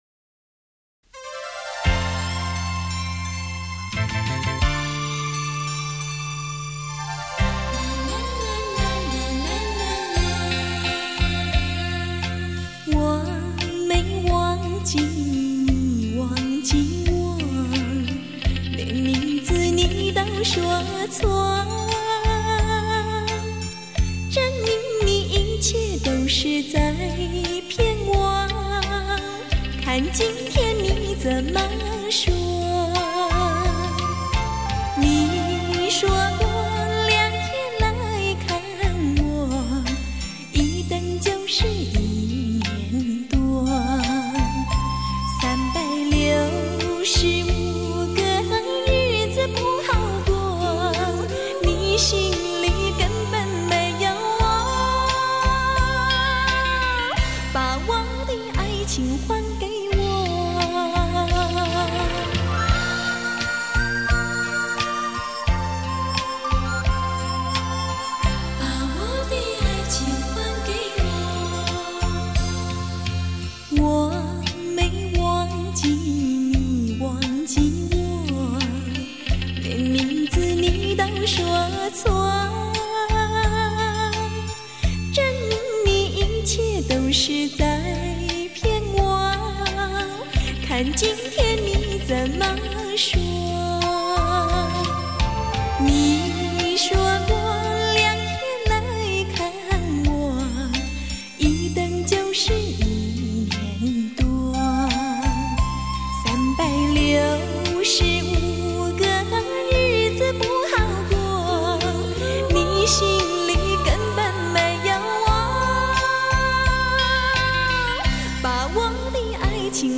詞藻清晰，表現出音樂的美感，唱腔極富感染力，很能表達出歌曲中的